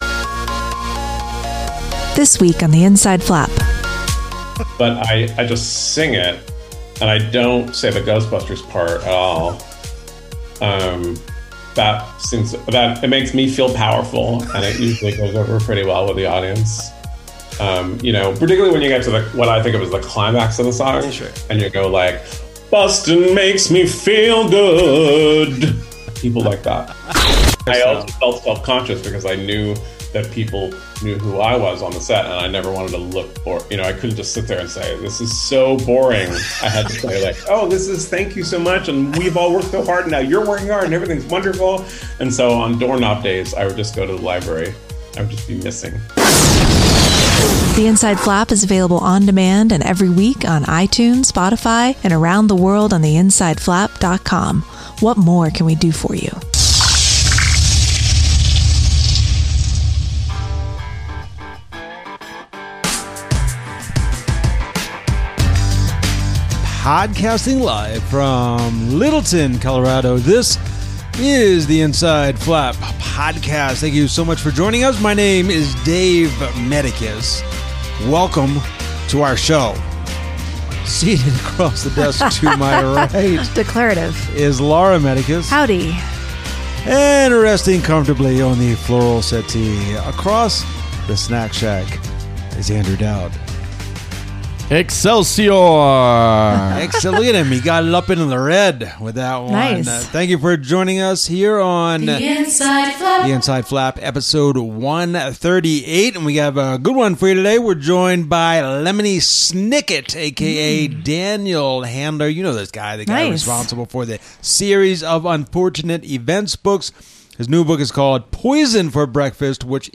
We’re joined by the hilarious Lemony Snicket for a fun chat about his new book Poison For Breakfast, stealing ideas from kids, the reality of being on set for filming, the reason he doesn’t want to narrate his books anymore, his hatred of PB&J sammies, the real reason he took up the accordion, creating first drafts that fill him with despair, and the rude, falling down encounter he had in a public library.